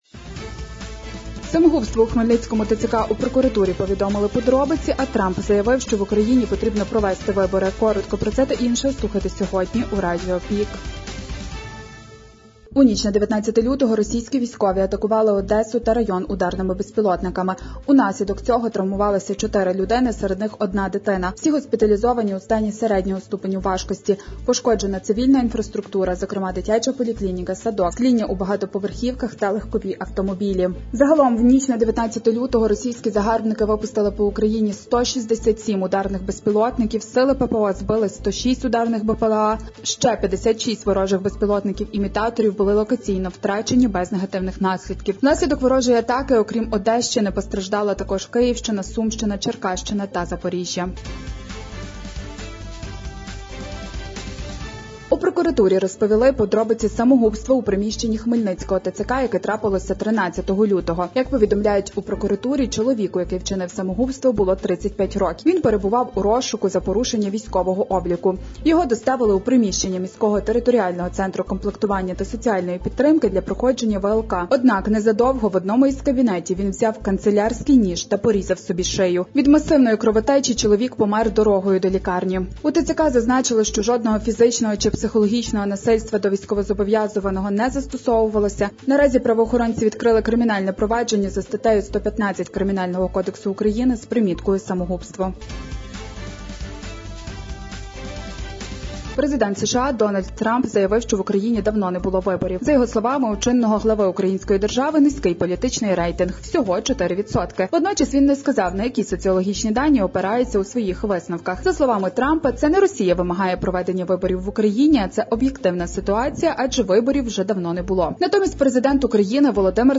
Пропонуємо Вам актуальне за день у радіоформаті.